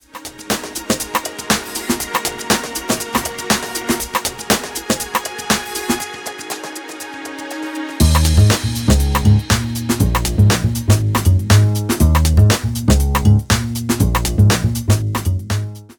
In this case we have dropped the main beat and even this creates enough space to distract the listener and signal the new section.
The percussion loop is also faded out over the drop using level automation and by the time the gap is bridged the new sections and bass part is introduced nicely.
The drop fill in action.